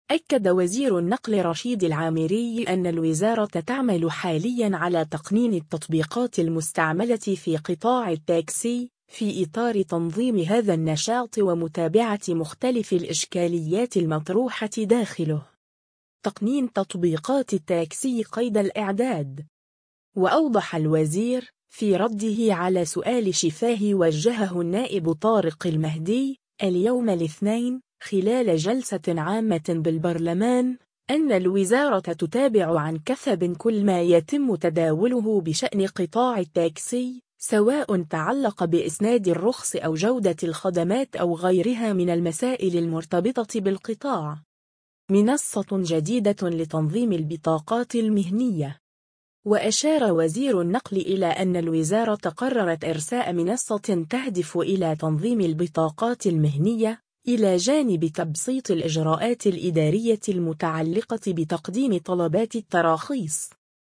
وأوضح الوزير، في رده على سؤال شفاهي وجّهه النائب طارق المهدي، اليوم الاثنين، خلال جلسة عامة بالبرلمان، أن الوزارة تتابع عن كثب كل ما يتم تداوله بشأن قطاع التاكسي، سواء تعلق بإسناد الرخص أو جودة الخدمات أو غيرها من المسائل المرتبطة بالقطاع.